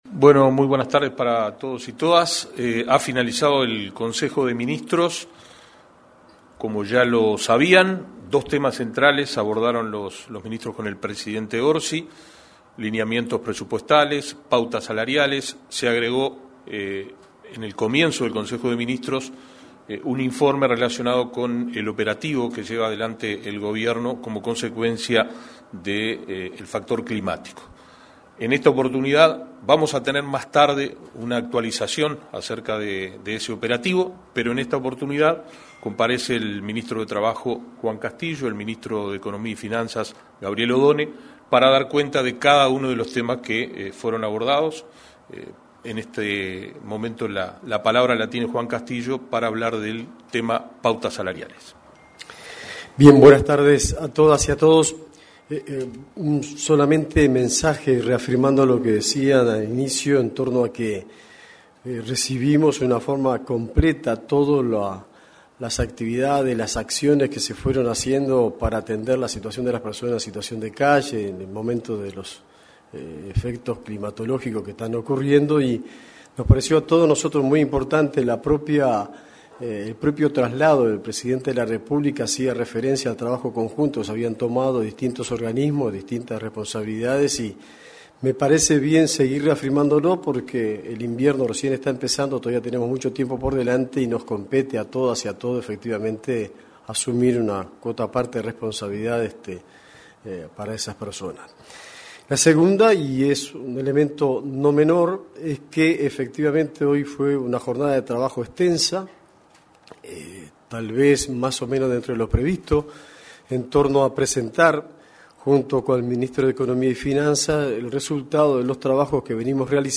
Conferencia de prensa tras el Consejo de Ministros
Conferencia de prensa tras el Consejo de Ministros 26/06/2025 Compartir Facebook X Copiar enlace WhatsApp LinkedIn Una vez finalizada la sesión del Consejo de Ministros, se expresaron en una conferencia de prensa los titulares de las carteras de Trabajo y Seguridad Social, Juan Castillo, y Economía y Finanzas, Gabriel Oddone.